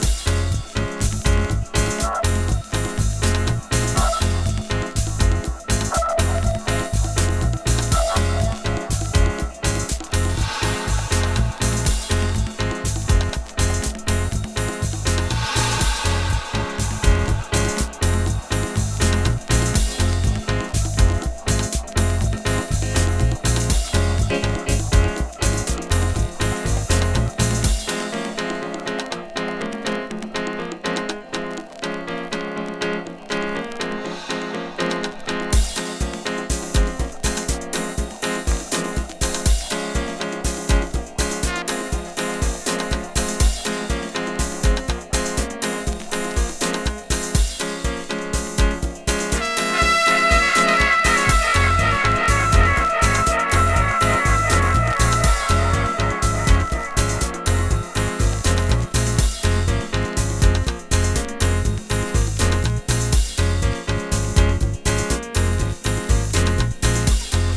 Deep過ぎず、オーガニックなDubby House！